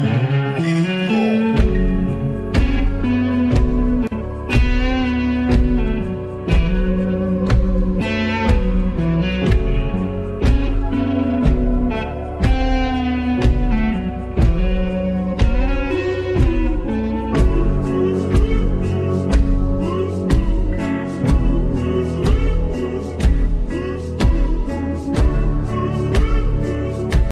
Alternative Ringtones